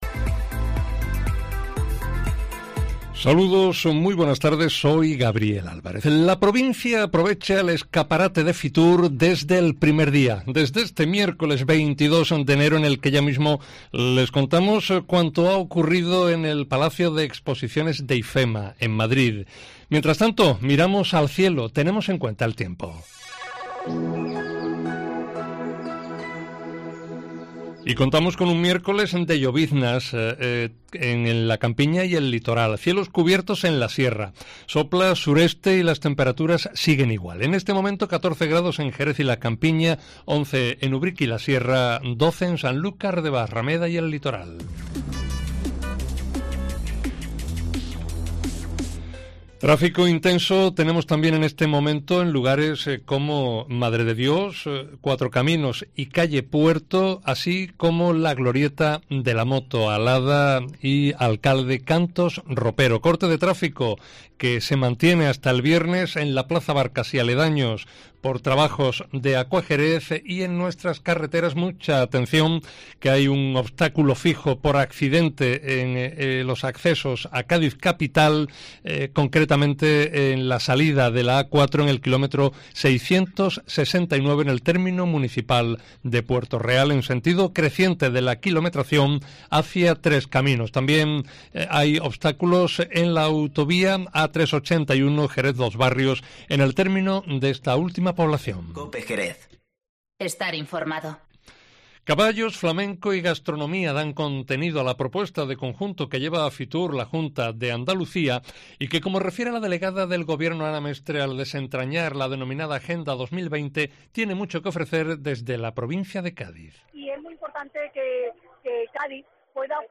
Informativo Mediodía COPE en Jerez 22-01-20